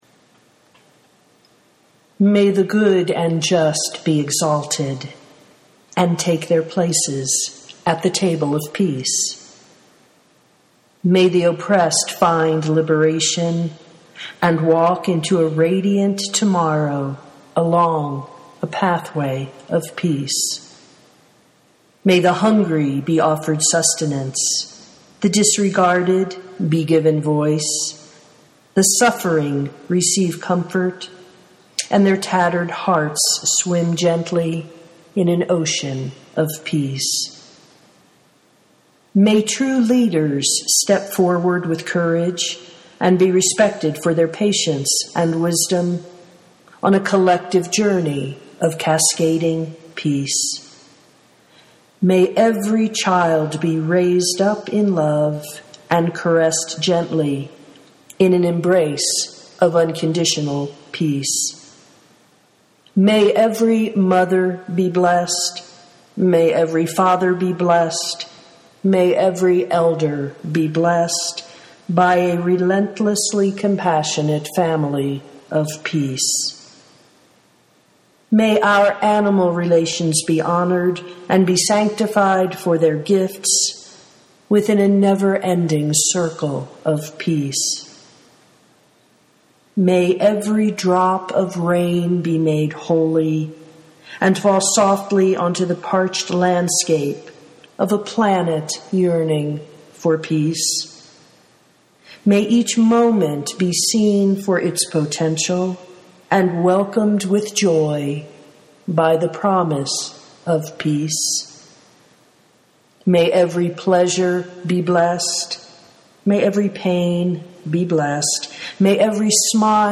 and may every prayer cry out for a world of peace (audio poetry 3:44)
Audio version (with that direct-from-The-Great-Void quality) is below.